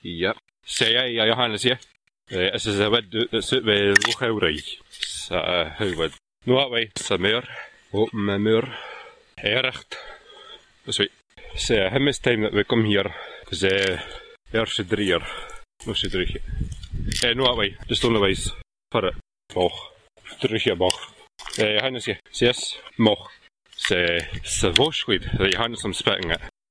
There are a few phonetic features I recognise from hearing English spoken by Shetlanders – trilled , glottal stops, realisation of RP /ʌ/ as [ʊ]. One feature that is not familiar to me, however, is the apparent pronunciation of as [s] or [z] – Shetlanders more often have [t] and [d] for .